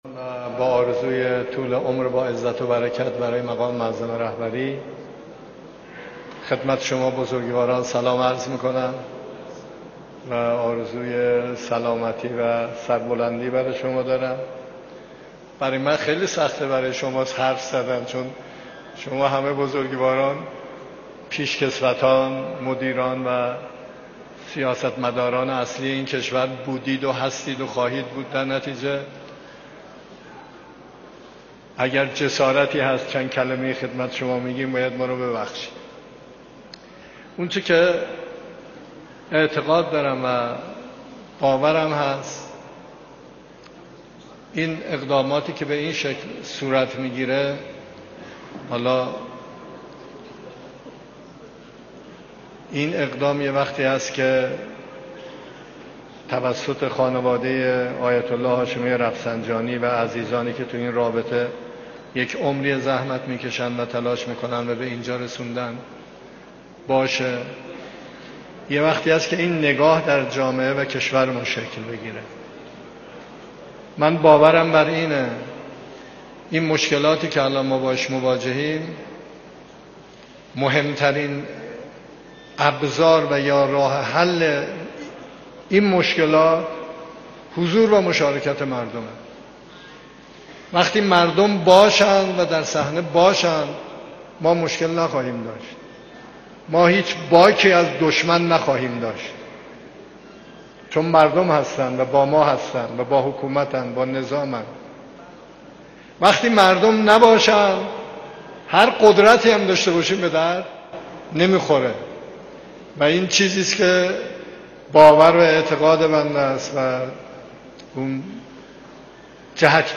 فایل سخنان دکتر مسعود پزشکیان رییس جمهور در مراسم افتتاح بیمارستان آیت الله رفسنجانی را گوش دهید: ثبت دیدگاه نام کاربری و موبایل شما منتشر نخواهد شد.